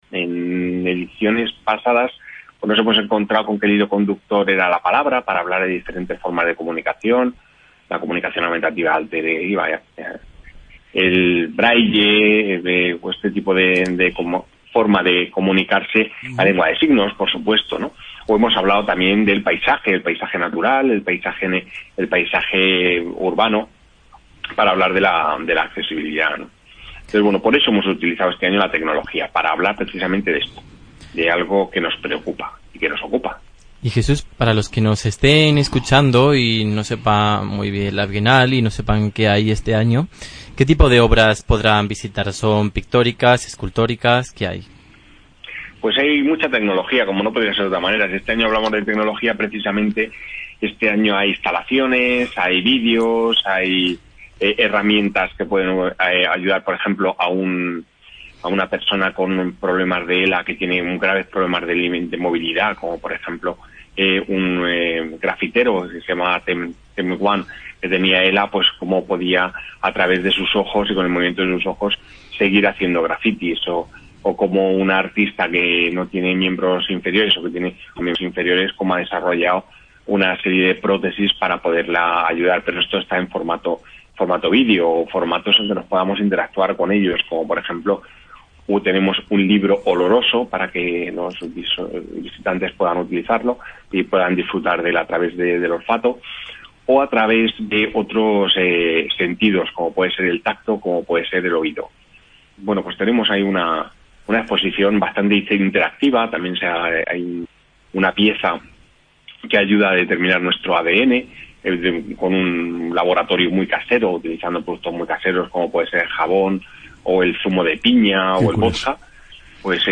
reflexionaba recientemente en Gestiona Radio sobre el valor de estos certámenes y talleres